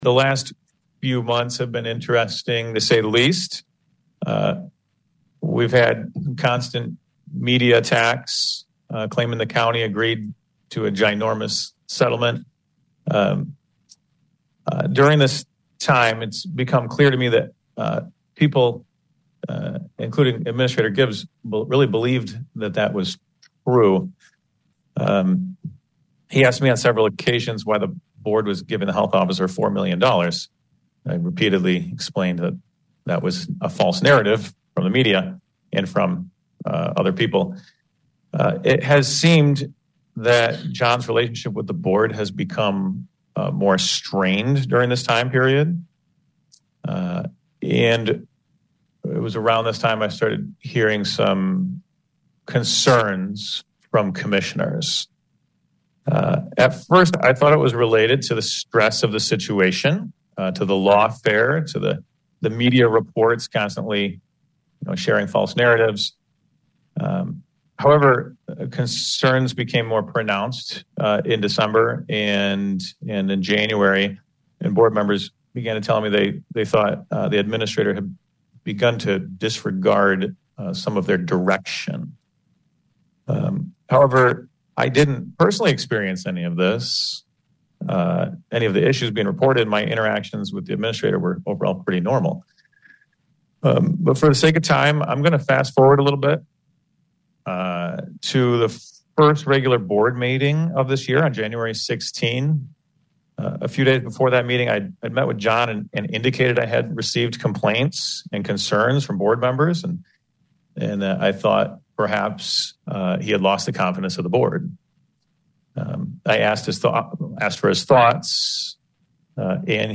Feb. 22, 2024; Olive Twp., MI; Ottawa County Board Chairman Joe Moss (R-Hudsonville) remarks during a special board meeting in the county Administration Building.
Before the board went into a closed door meeting on the matter, Moss made the following statement.